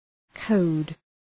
Προφορά
{kəʋd}